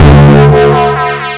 Desecrated bass hit 12.wav